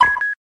03 Shutter Sound 03.mp3